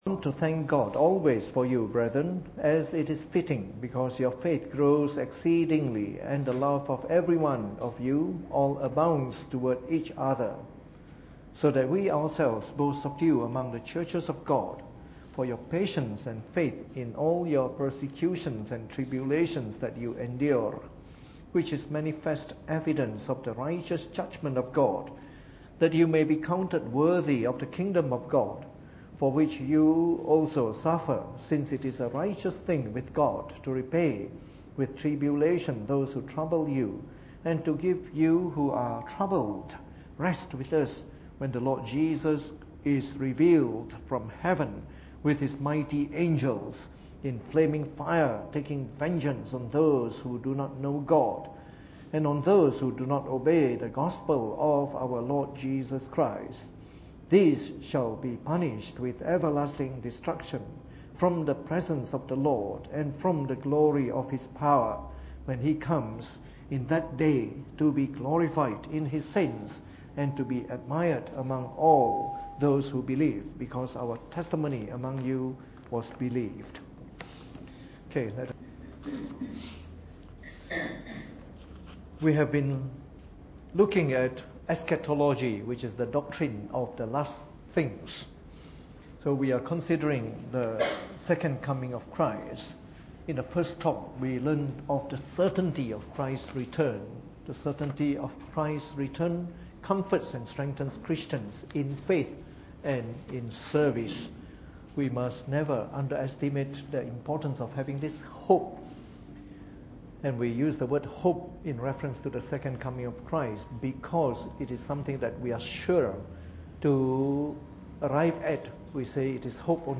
Preached on the 20th of August 2014 during the Bible Study, from our new series of talks on Eschatology.